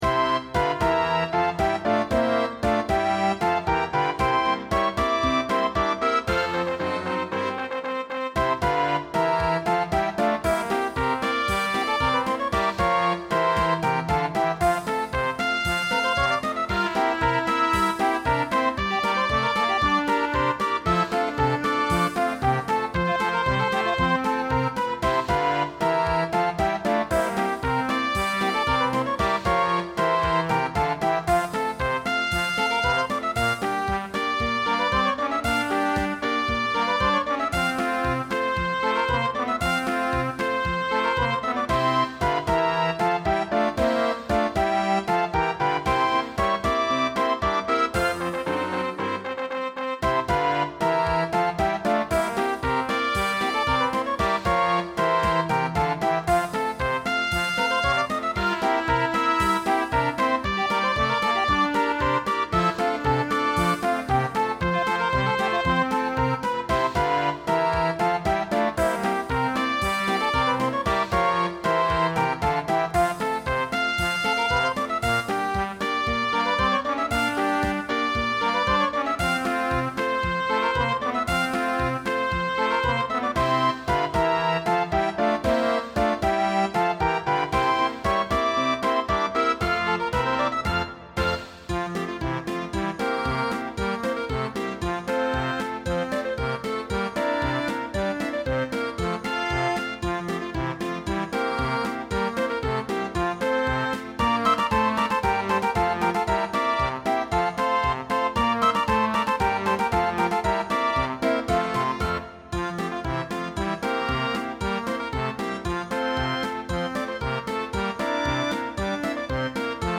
Midi performance
This is an instrumental piece written 100 years ago for Palisades Amusement Park. It has the great feel of typical ragtime and march tunes that were so commonplace during the period.